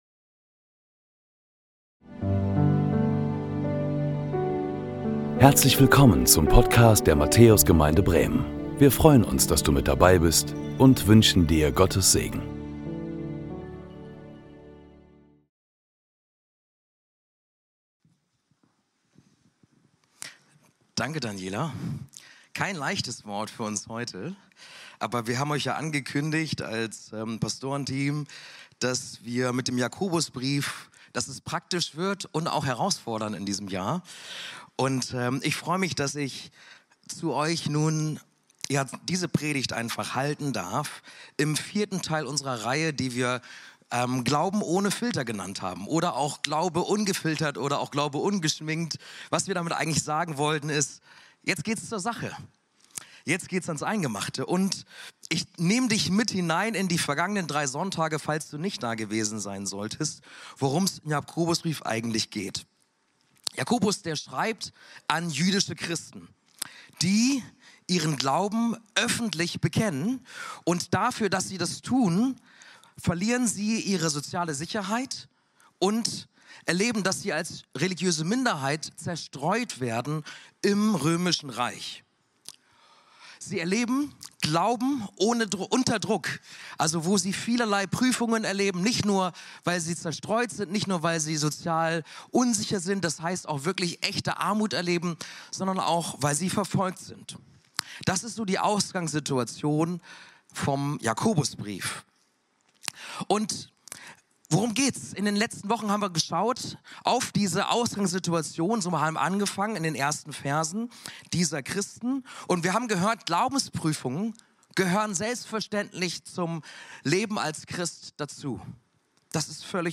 Beschreibung vor 2 Monaten Willkommen zu unserem 10 Uhr Gottesdienst live aus der Matthäus Gemeinde Bremen!